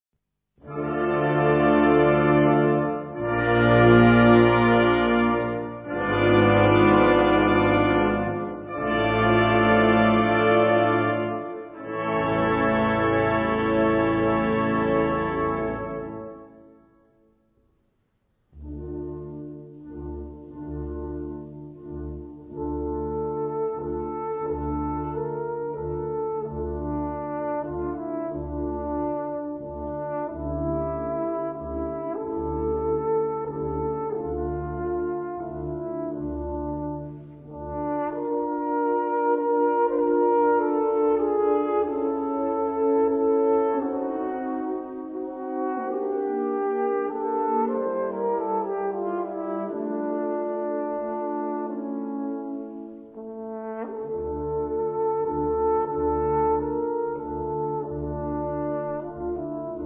Gattung: Solostück
Besetzung: Blasorchester